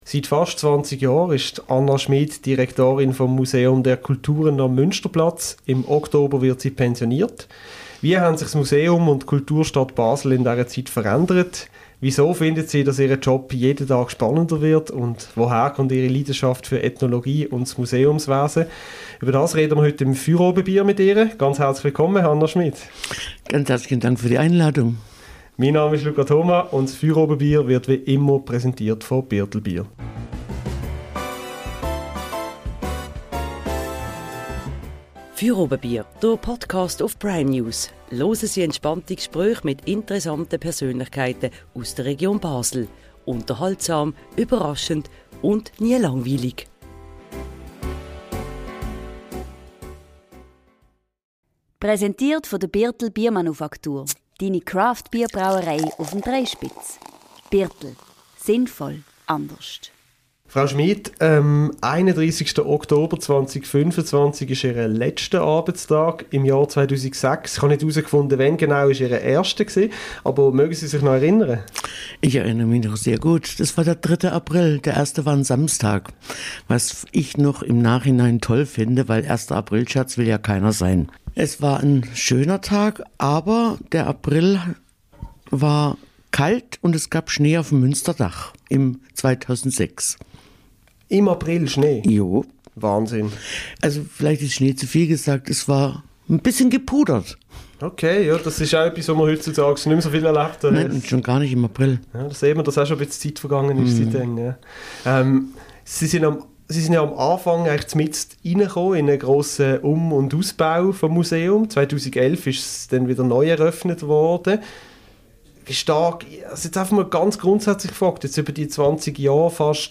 Fürobebier ist die wöchentliche Diskussionssendung des Basler Onlineportals Prime News. Jeweils am Montag diskutieren Mitglieder der Redaktion zusammen mit einem Gast über drei Themen, welche aktuell die Stadt und die Region Basel beschäftigen.